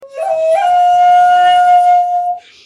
Shakuhachi 59